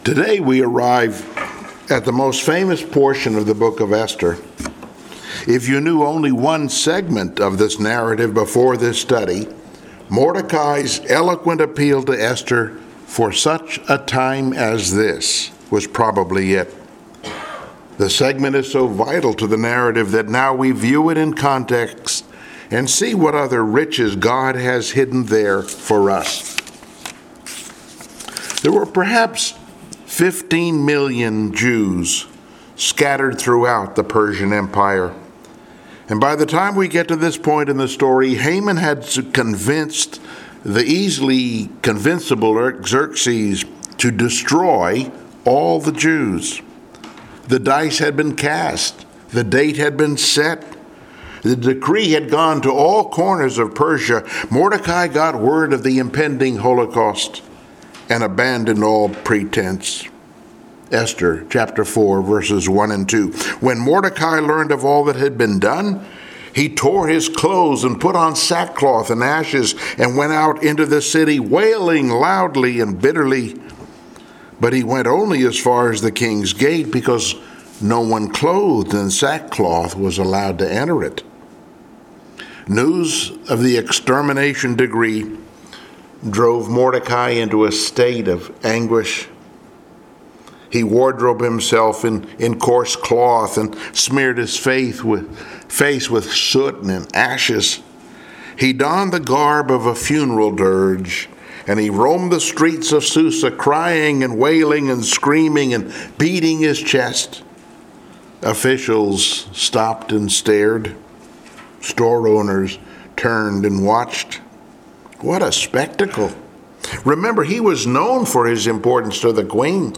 Passage: Esther 4 Service Type: Sunday Morning Worship